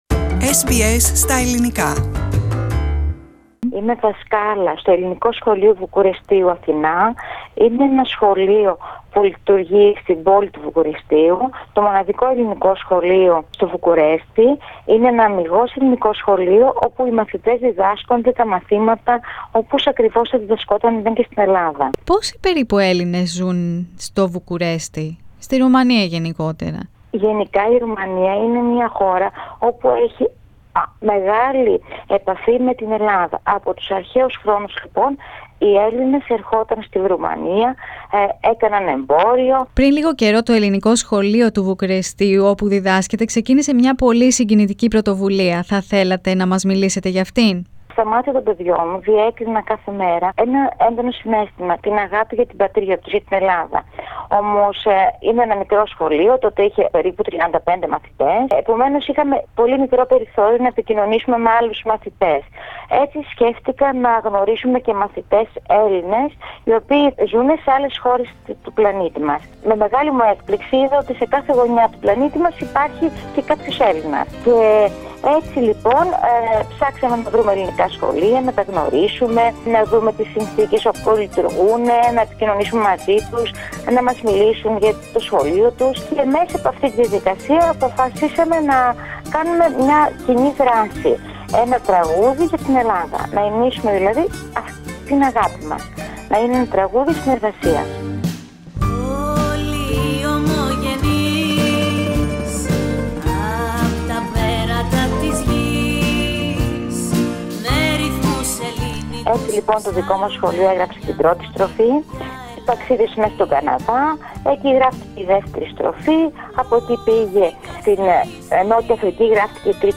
Διαβαστε επισης Δημοσιογράφος ξεκίνησε να αναζητά Έλληνες ανά την υφήλιο Πατήστε Play στο Podcast που συνοδεύει την κεντρική φωτογραφία για να ακούσετε τη συνέντευξη.